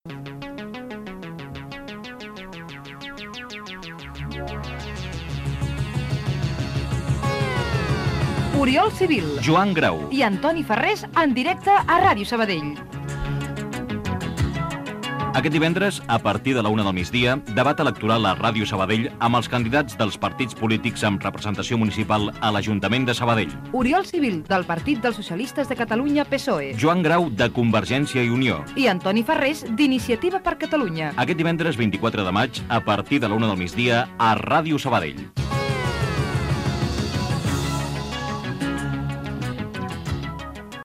Promoció del debat electoral de les eleccions municipals de 1991 a Sabadell que farà el programa Panorama
Informatiu